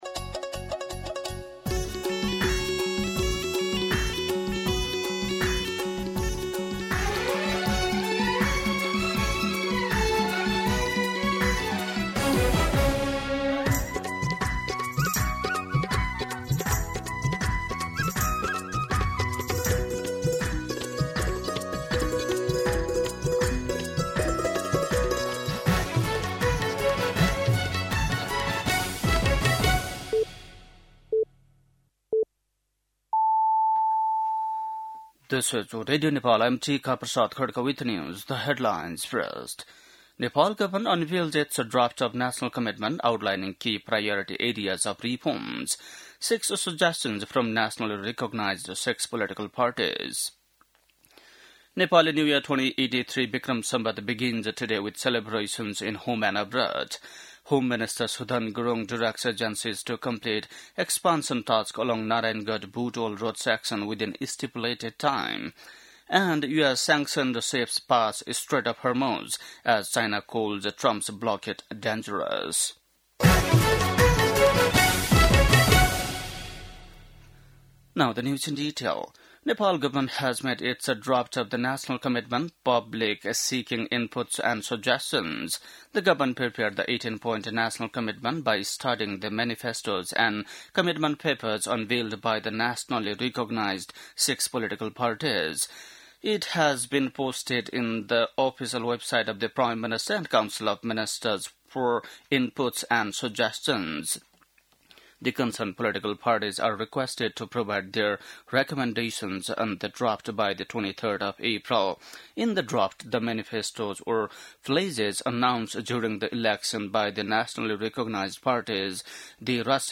बेलुकी ८ बजेको अङ्ग्रेजी समाचार : १ वैशाख , २०८३
8-pm-news-1.mp3